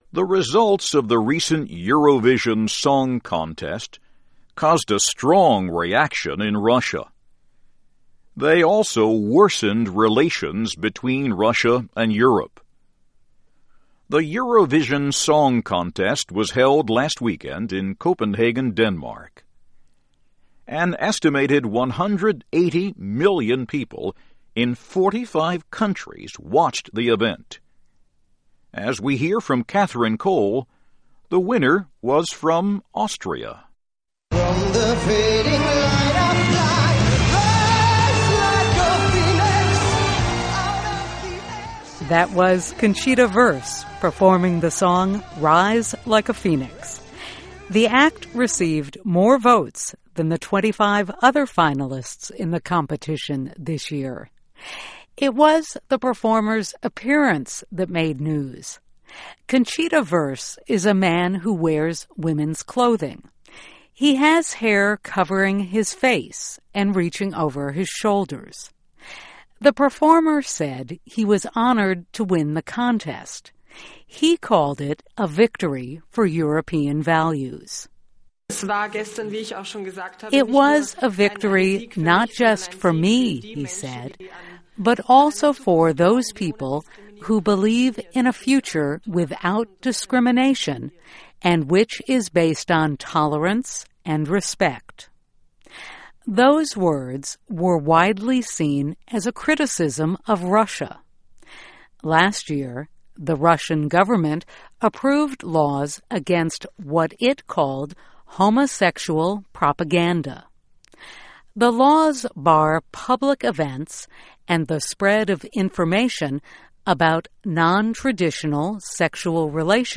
by VOA - Voice of America English News